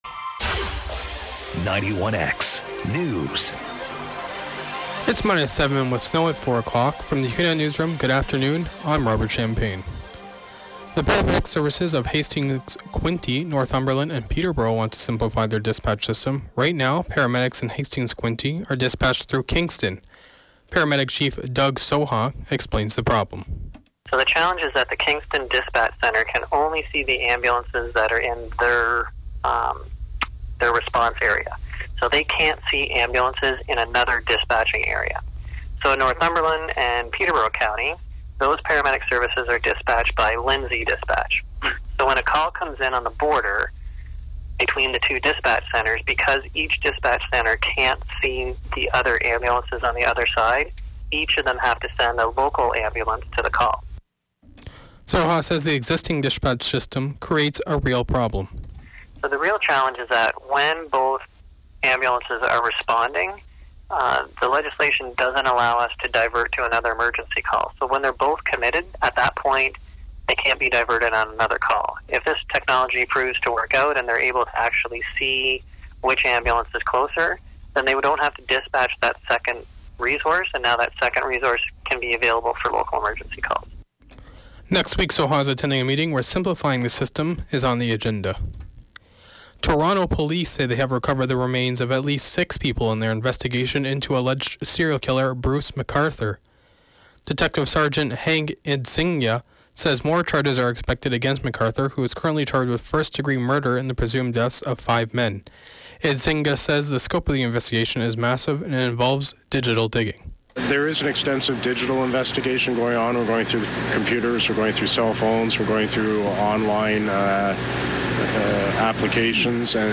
91X Newscast: Thursday, Feb. 8, 2018, 4 p.m.